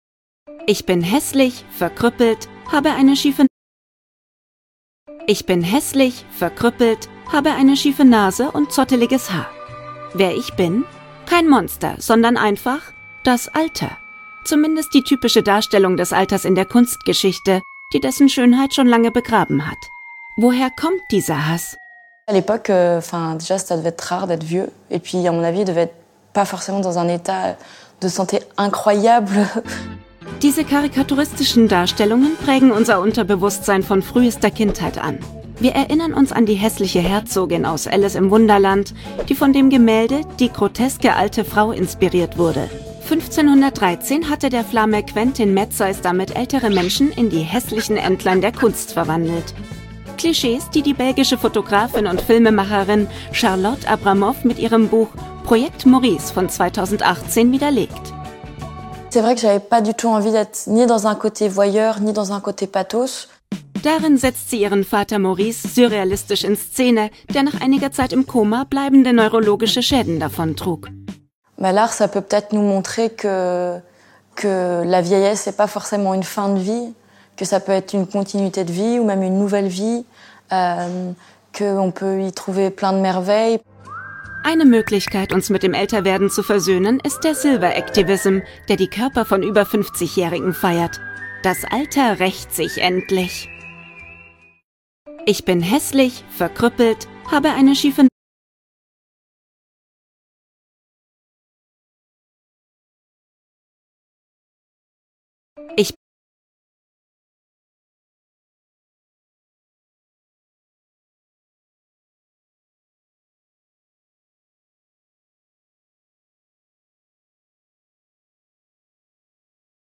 ARTE | als: Kommentar-Stimme “FlickFlack”